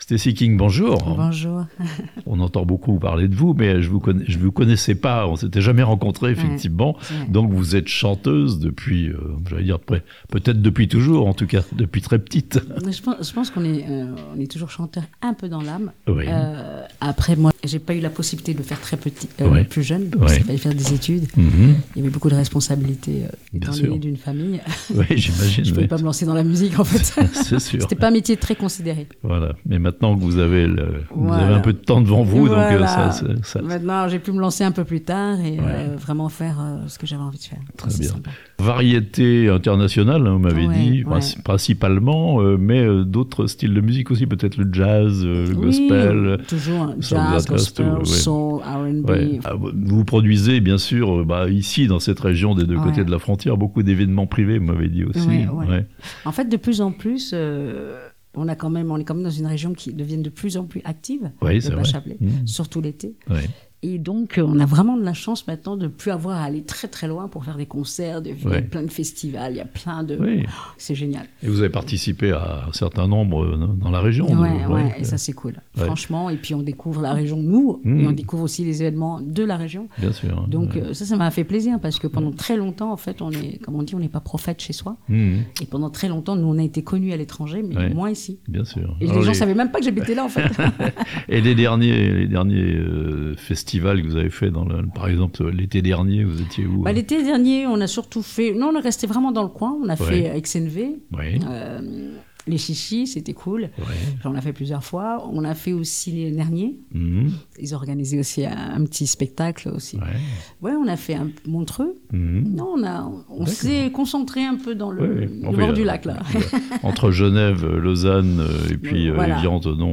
au studio thononais de La Radio Plus